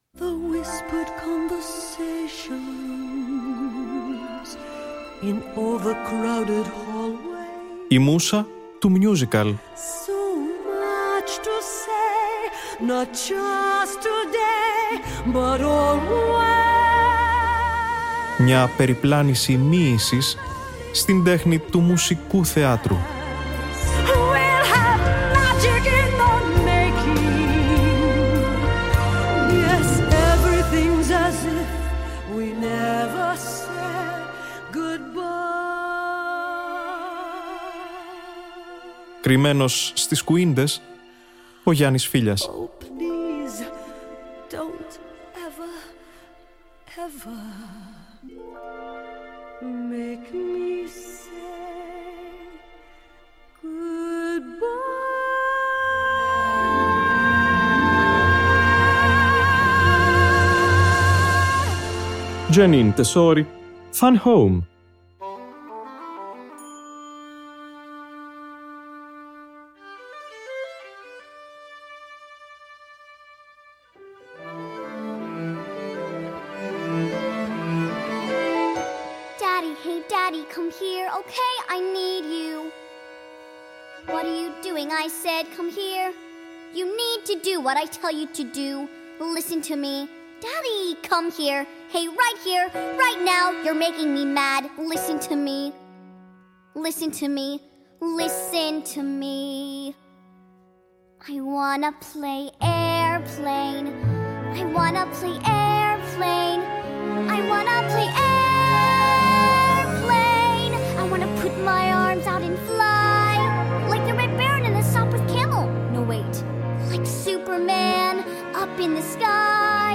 Η ηχογράφηση του original Broadway cast που θα απολαύσουμε αυτή την Τετάρτη, συγκεντρώνει τους περισσότερους ερμηνευτές και της αρχικής off Broadway παραγωγής, ενώ προτάθηκε για Grammy το 2016.